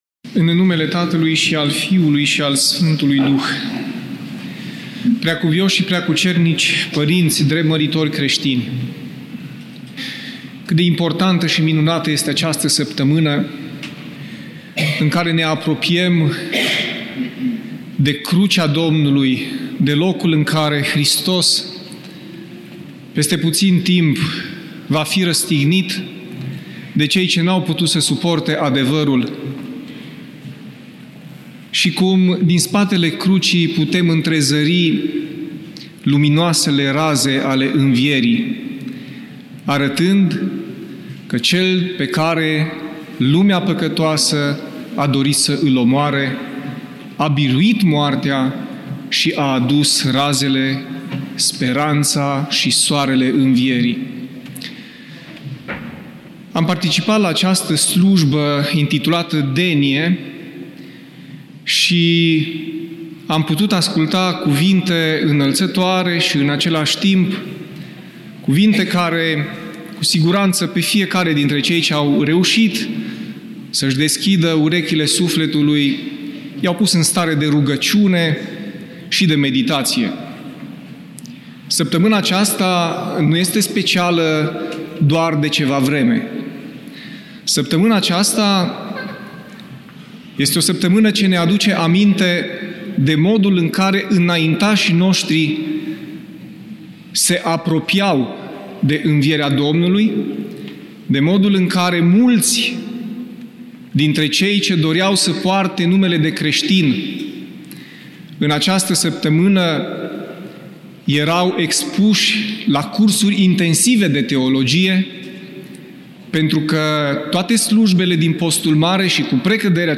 Cuvinte de învățătură Cuvânt în Sfânta și Marea Zi de Miercuri